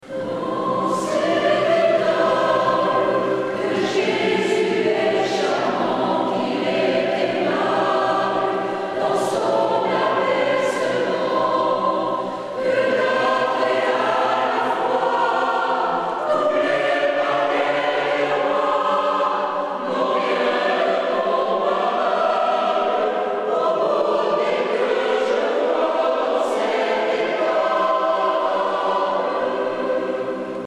Choeur mixte